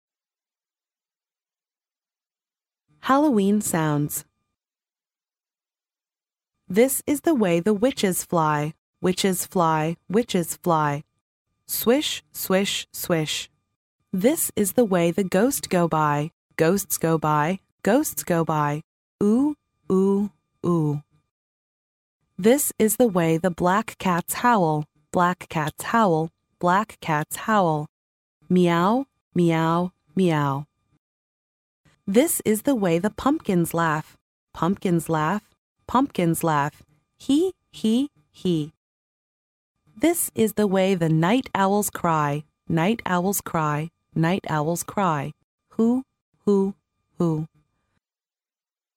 幼儿英语童谣朗读 第19期:万圣节的声音 听力文件下载—在线英语听力室